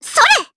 Rehartna-Vox_Attack2_jp.wav